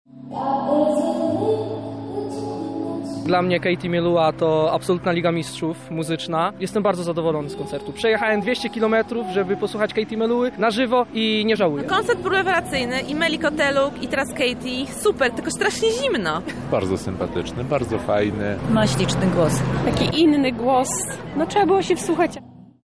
Na Arenie Lublin w ramach Europejskiego Festiwalu Smaku wystąpiła Katie Melua.
Jakie wrażenia miała publiczność, sprawdziła to nasza reporterka